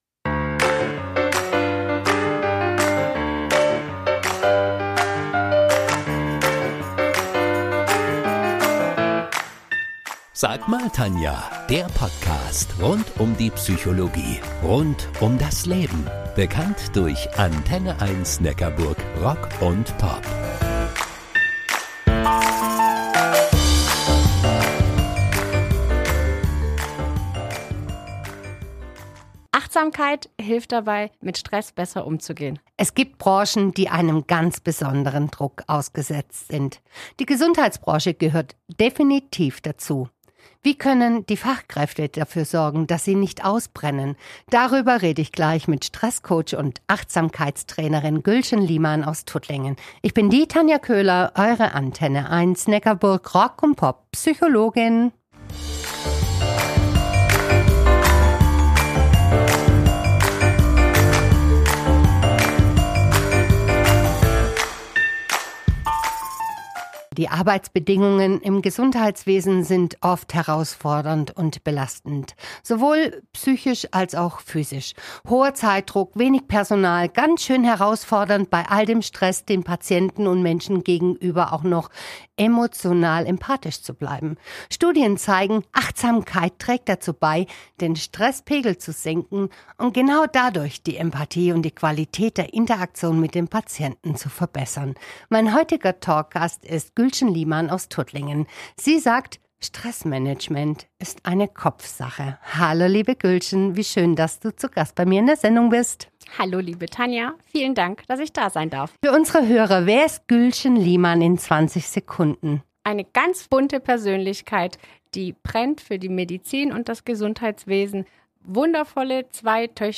Im Gespräch mit Achtsamkeits- und Streßcoach